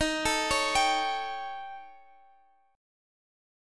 Listen to D#7b5 strummed